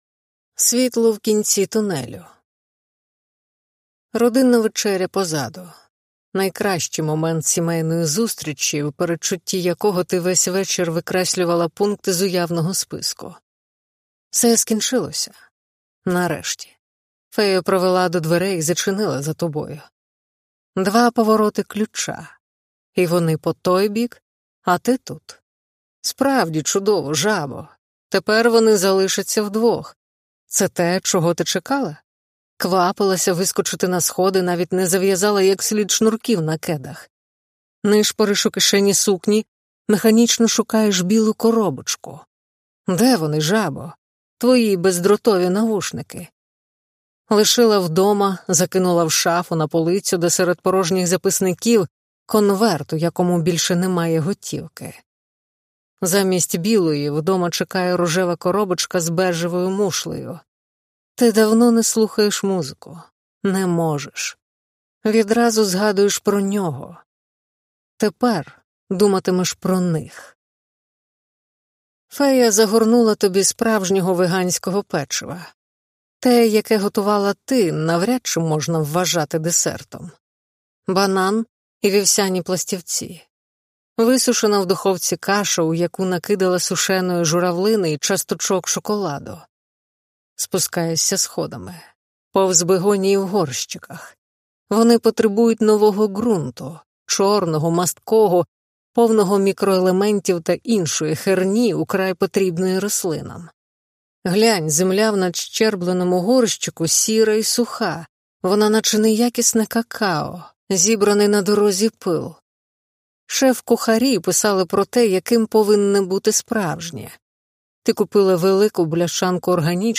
Господь не любить веганів: аудіокнига українською – Ганна Городецька